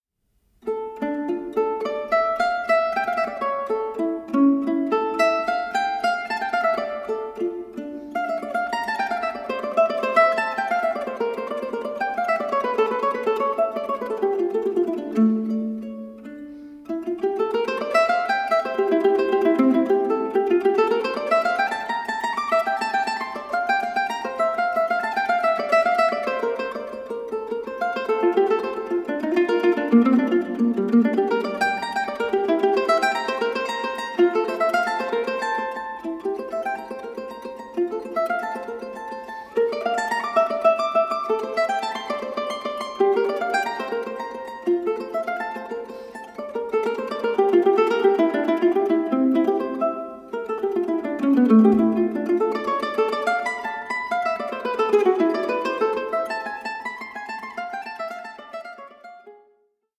FEATURING MANDOLINS, MANDOLA, LUTE AND BAĞLAMA